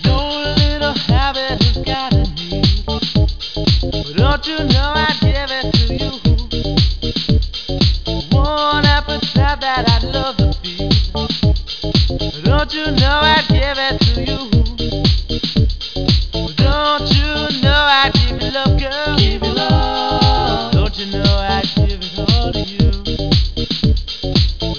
electronic music
clearly brings the beats to the front of this song
keyboards, samplers, vocals